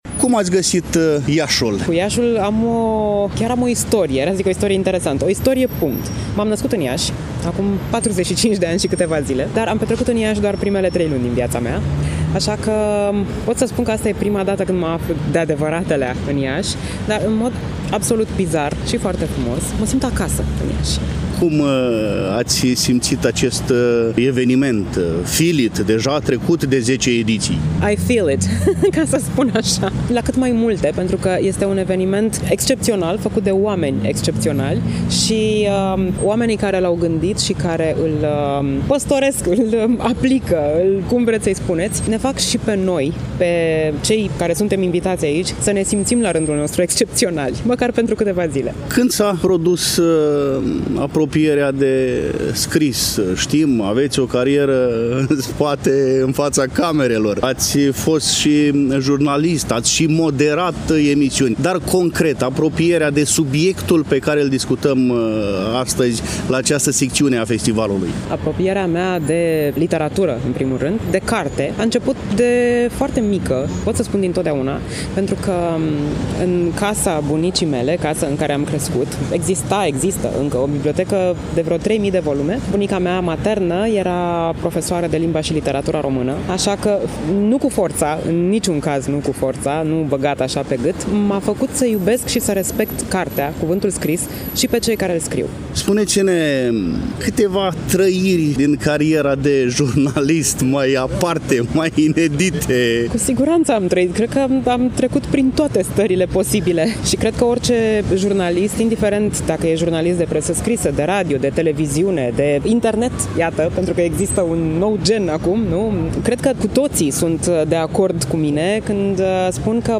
Dragi prieteni, de la ediția a XI-a, 2023, a Festivalului Internațional de Literatură și Traducere de la Iași, ne purtăm pașii spre ediția a XII-a, 2024.